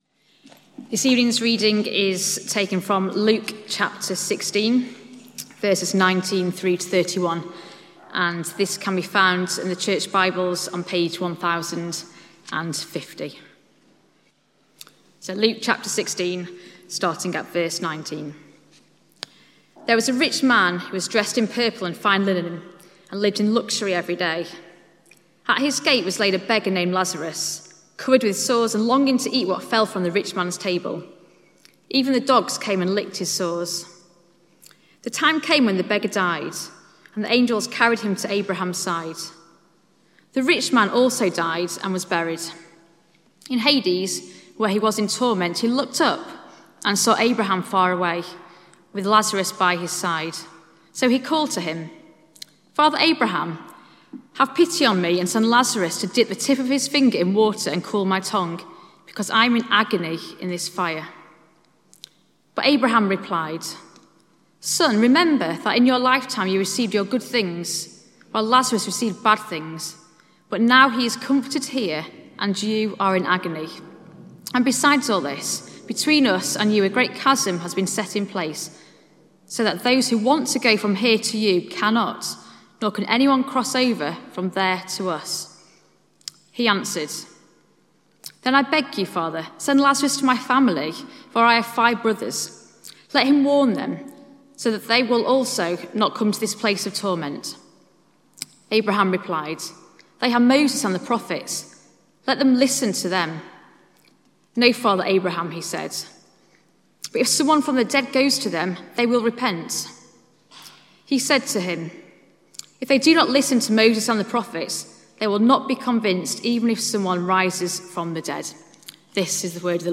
From Service: "6:00 pm Service"